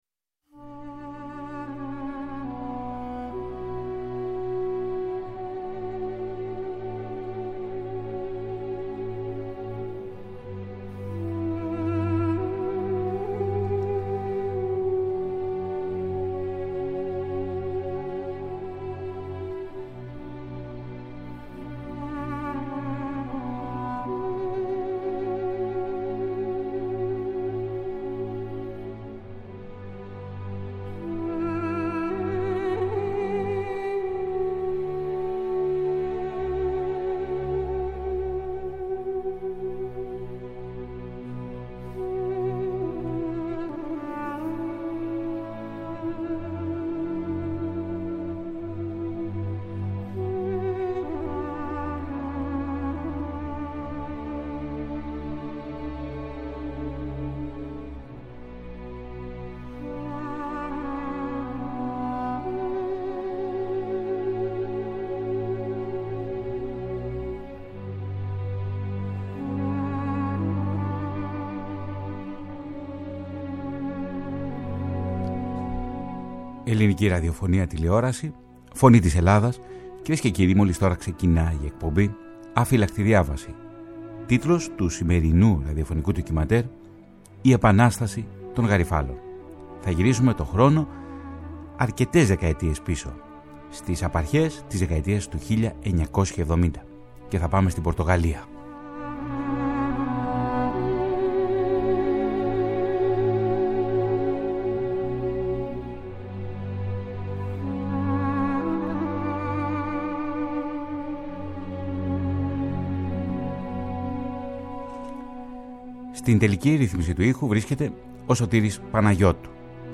Παρουσιάζονται οι πολλαπλές όψεις της διακυβέρνησης Σαλαζάρ, ενώ ακούγεται και ένα σπάνιο ηχητικό τεκμήριο με τον ίδιο τον δικτάτορα από το 1966. Με ηχητικά τεκμήρια από τη δημόσια ραδιοφωνία της Πορτογαλίας αλλά και επαναστατικά τραγούδια της εποχής αποτυπώνεται η εικόνα μίας λαϊκής εξέγερσης, που μπορεί να μην ήταν επανάσταση στην αυστηρή εννοιολογική της διάσταση, αλλά νοηματοδότησε την ιστορία της Πορτογαλίας το τελευταίο τέταρτο του προηγούμενου αιώνα.